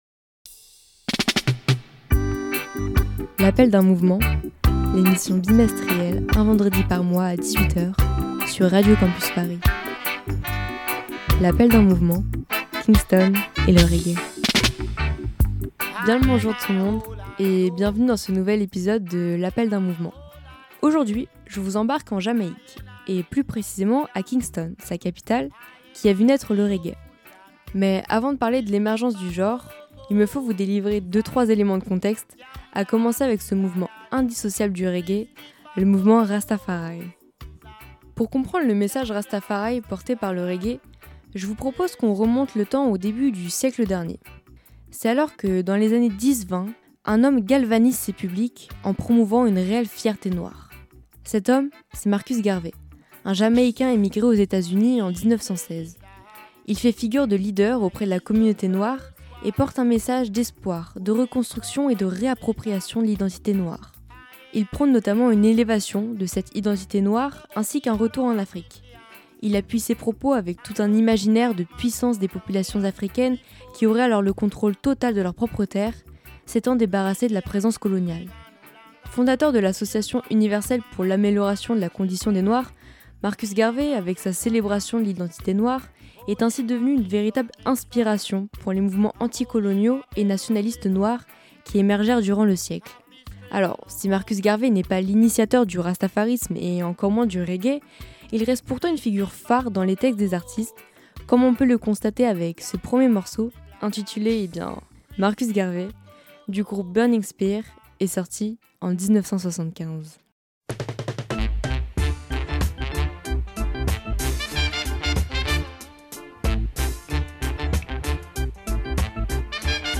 Alors, c'est avec ces quelques indications en tête que je vous laisse découvrir cet épisode, dans lequel les musiques pourraient, finalement, se suffire à elles-mêmes.